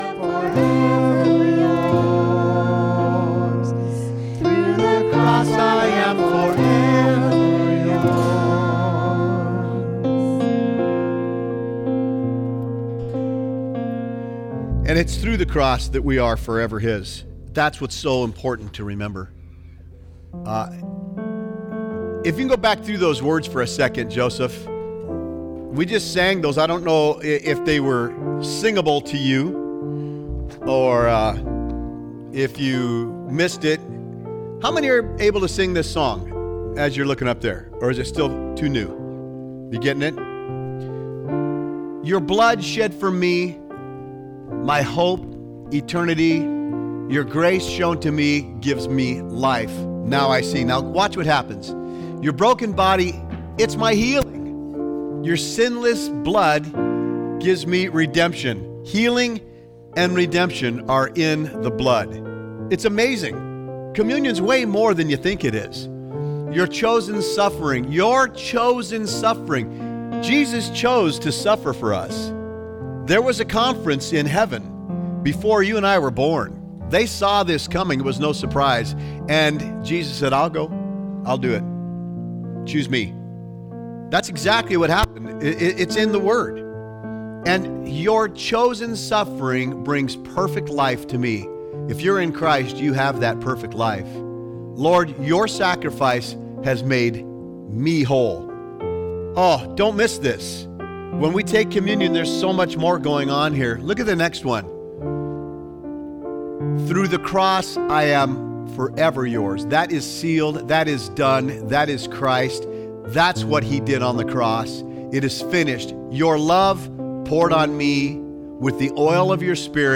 11-3-24-COMMUNION-FULL-SERVICE.mp3